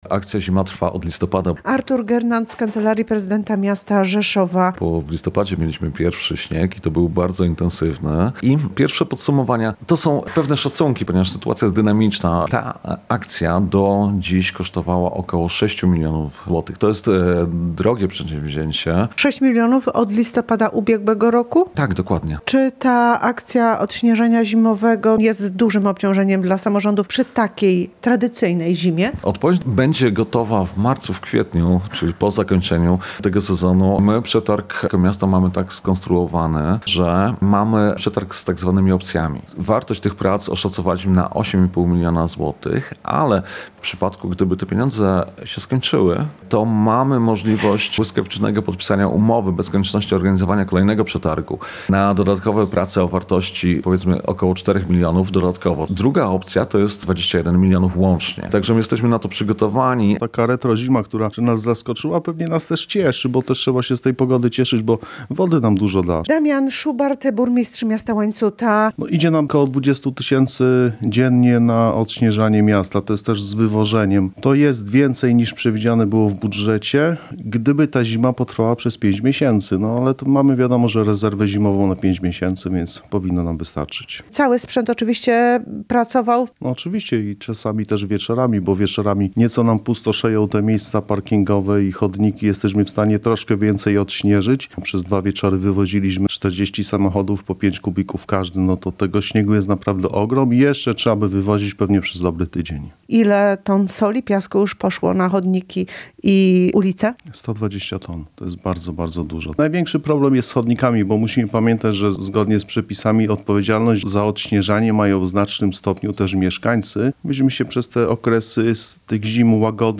Opady śniegu i gołoledź podnoszą wydatki • Relacje reporterskie • Polskie Radio Rzeszów